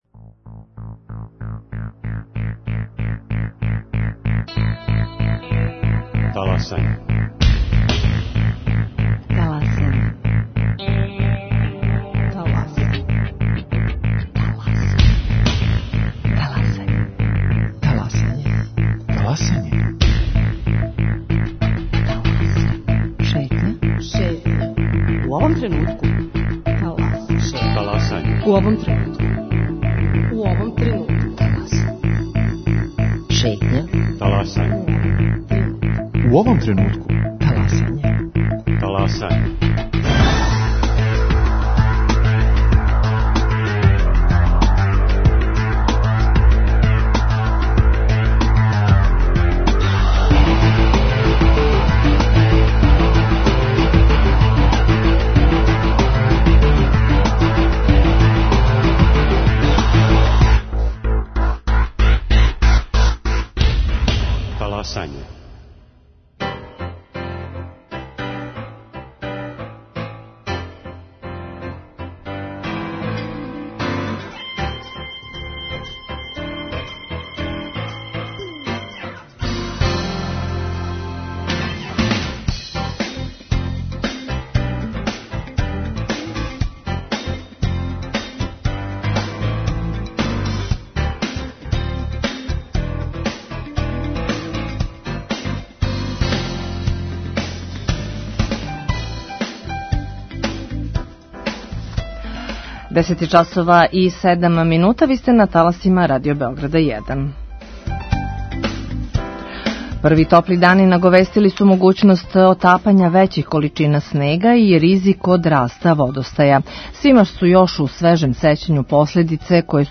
Колико је Србија за пет година унапредила инфраструктуру за заштиту од поплава? Шта је урађено, а шта још недостаје питаћемо директора Канцеларије за управљање јавним улагањима Марка Благојевића.
У првом сату магазина Шетња слушаћемо и причу некада најбољег ватерполисте света, Данила Даче Икодиновића.